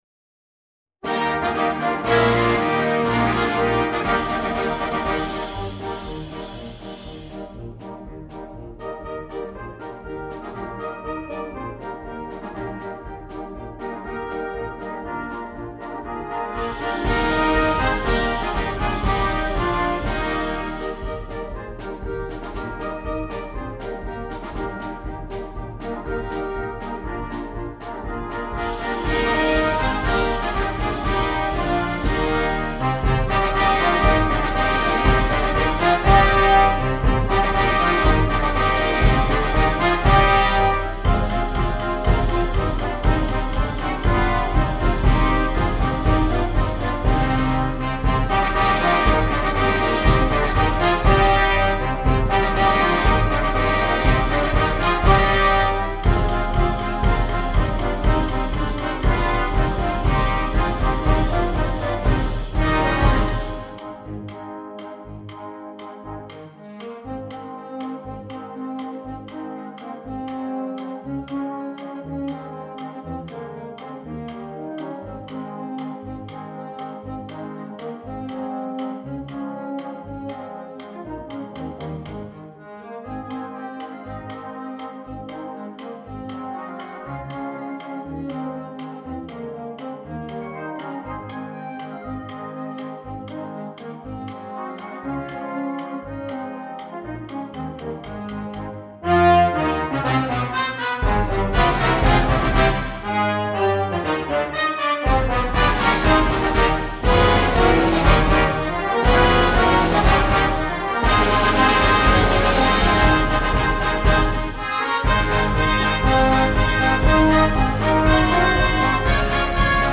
medley su celebri motivi natalizi
MUSICA PER BANDA